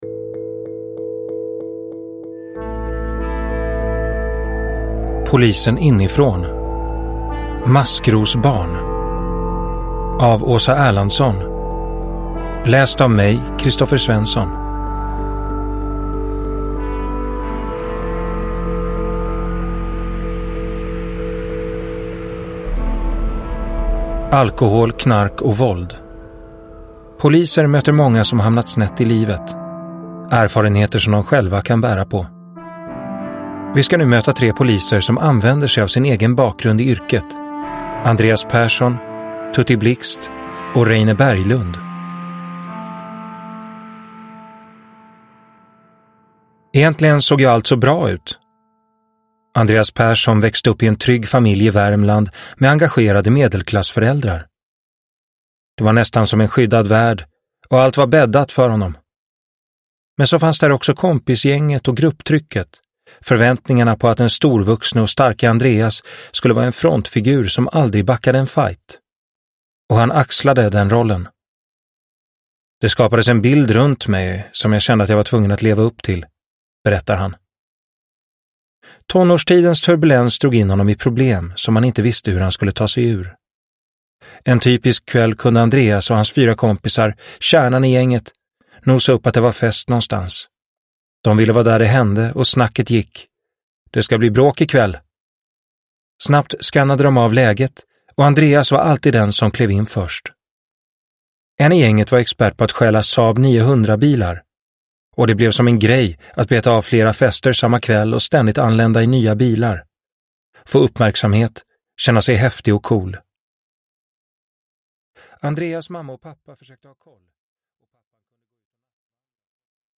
Maskrosbarn – Ljudbok – Laddas ner